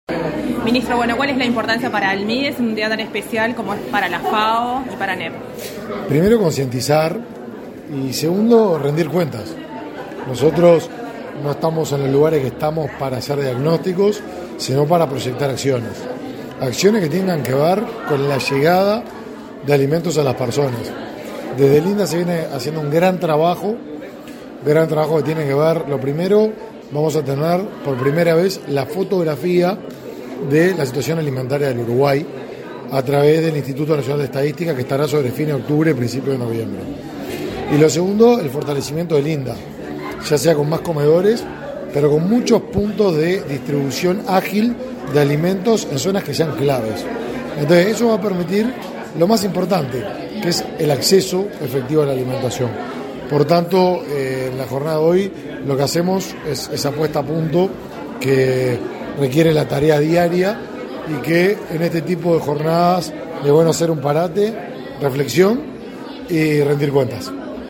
Entrevista al ministro de Desarrollo Social, Martín Lema
La Dirección de Derechos Humanos de la ANEP organizó un encuentro por el Día Mundial de la Alimentación, efectuado este 12 de octubre.
El ministro de Desarrollo Social, Martín Lema, realizó declaraciones a Comunicación Presidencial.